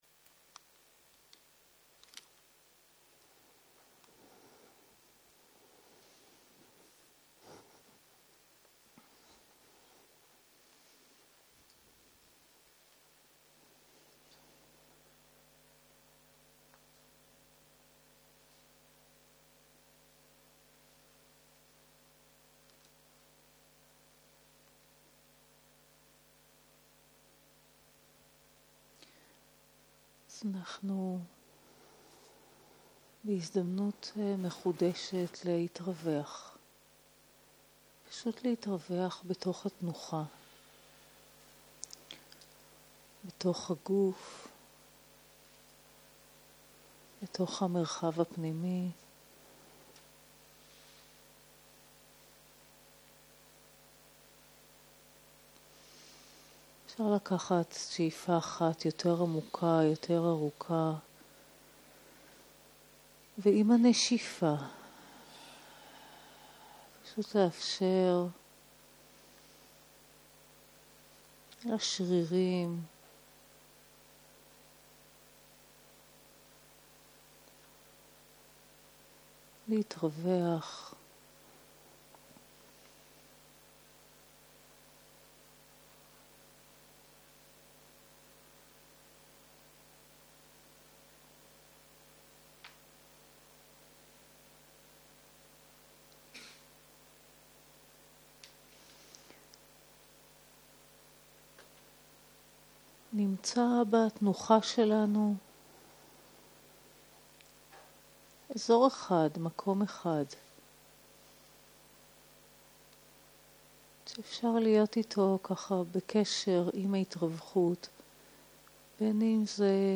15.03.2023 - יום 1 - ערב - מדיטציה מונחית - נוכחות, רכות - הקלטה 1